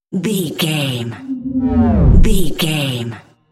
Deep whoosh pass by
Sound Effects
dark
futuristic
whoosh